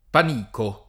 pan&ko] s. m. («graminacea; becchime»); pl. (raro) -chi — es.: chi ha paura di passere, non semini panico [k& a ppa2ra di p#SSere, non S%mini pan&ko], proverbio — sim. i cogn. Panichi, Panico; così pure le vie del Panico (a Roma, a Firenze)